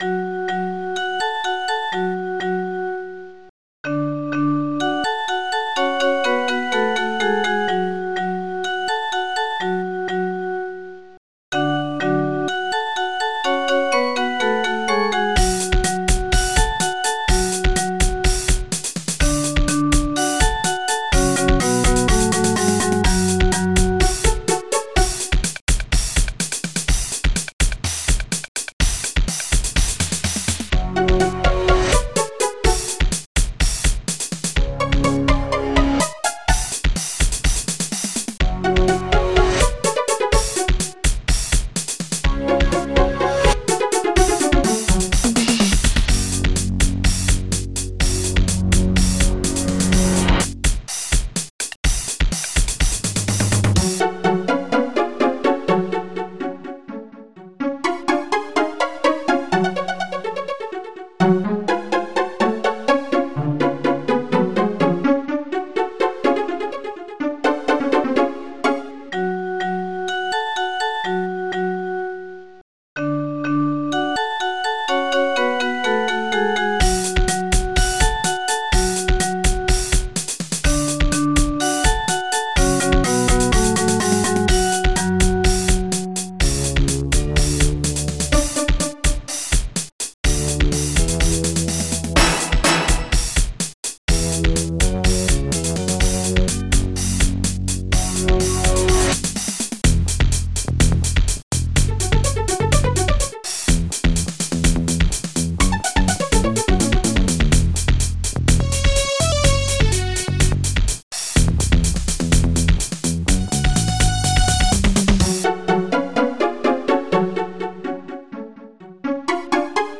SHORTSNARE
CLOSED.HAT
CRASHCYMBAL.STE
TOMDRUM.STE
GUITAR.PLUCKED
BELL&SYNTHBASS
XYLOPHONE
REVERSE.PIANO
STRINGS.PIZZICATO